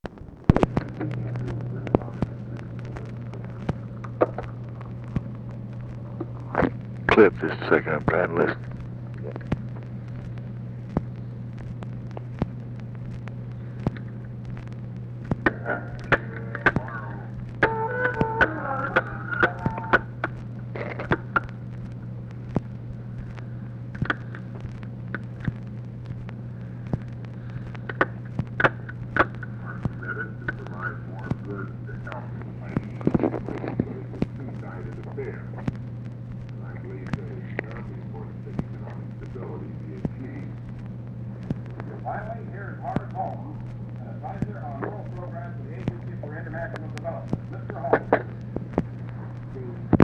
OFFICE CONVERSATION, February 20, 1966
Secret White House Tapes | Lyndon B. Johnson Presidency